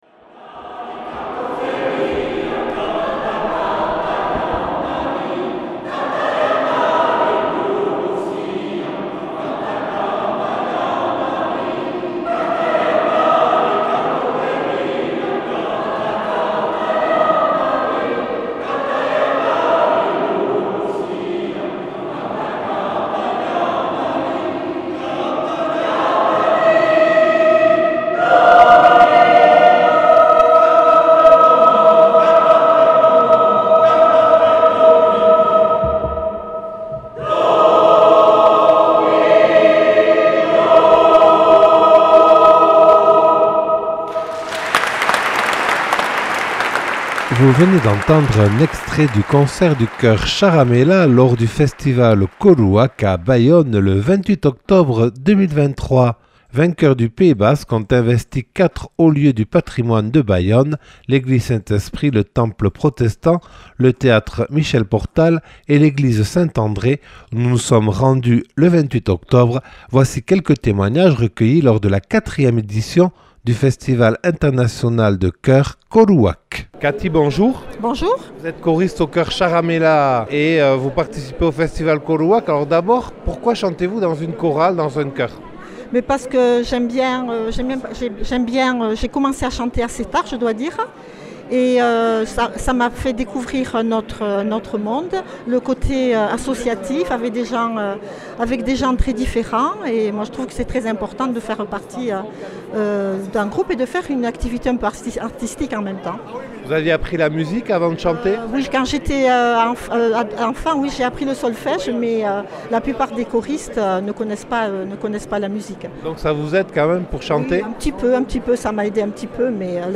Témoignages recueillis le 28 octobre 2023 en l'église Saint-André de Bayonne.